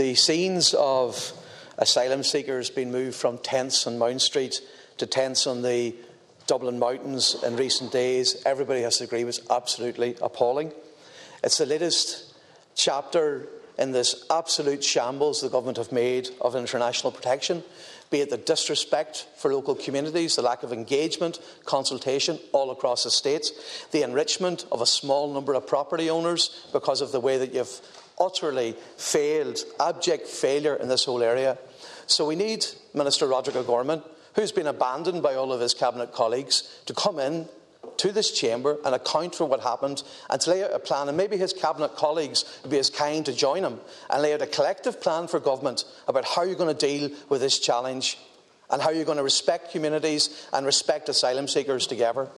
Deputy Padraig MacLochlainn says the Government needs to respect communities and those seeking international protection here: